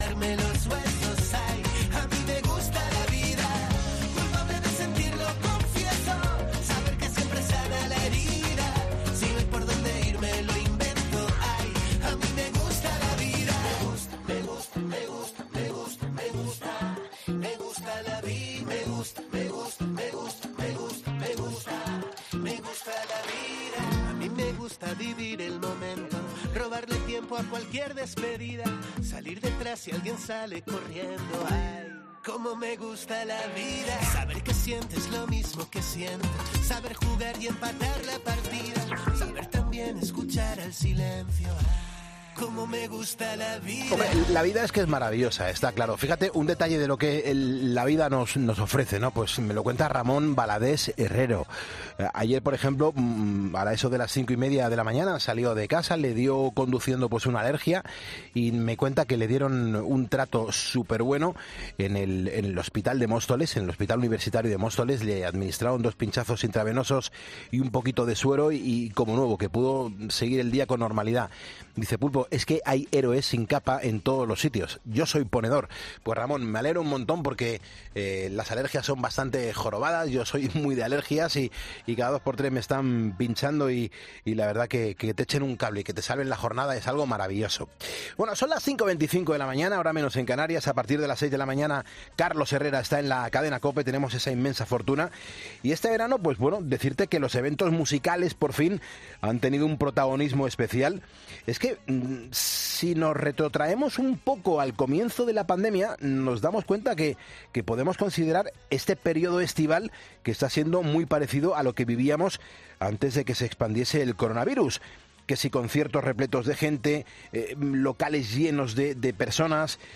En 'Poniendo las Calles' hablamos con Diego Cantero, conocido artísticamente como Funambulista